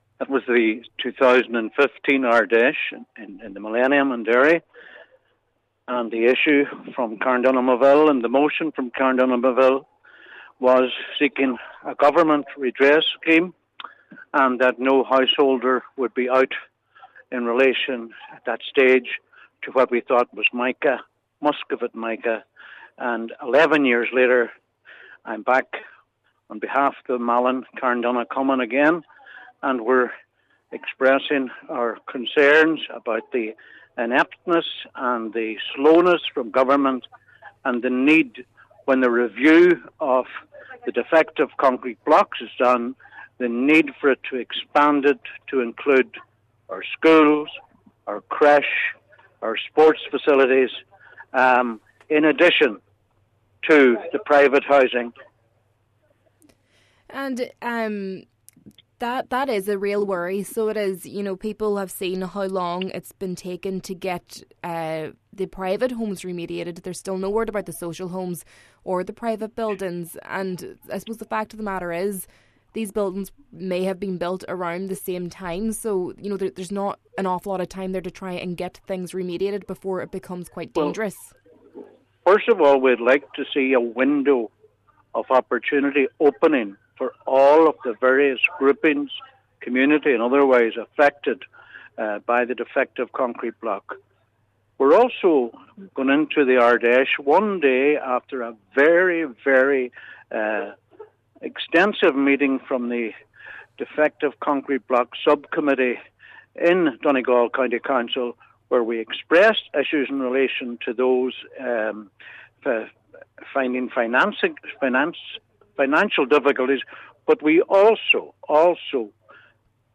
An Inishowen councillor says he is repeating calls for action on defective concrete at the Sinn Féin Ard Fheis in Belfast that he first made over a decade ago.